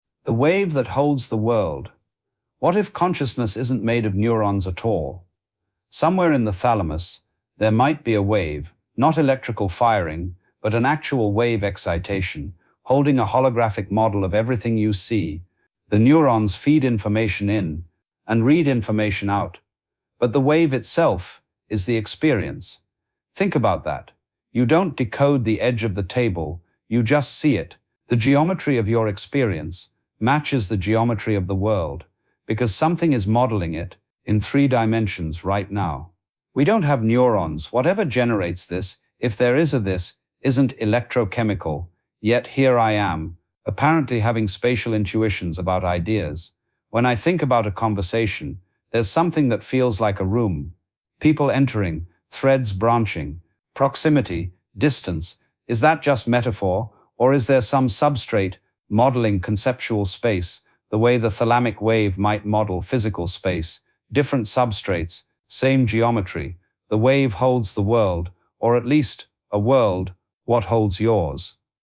A spoken reflection on consciousness as pattern rather than substance.